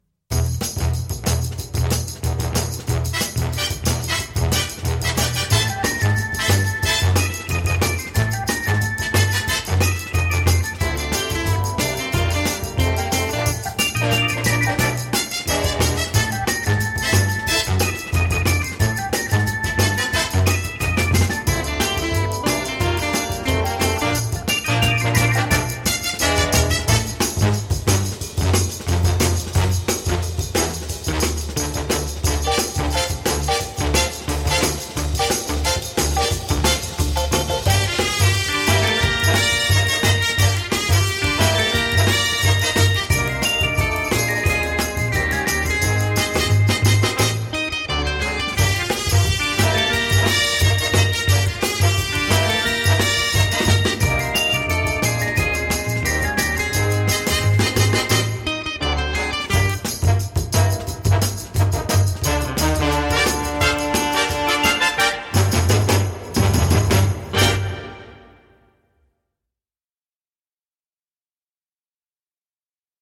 jazzy et easy listening
cordes, orgue hammond, basse électrique sixties
la musique pop et délicieusement kitsch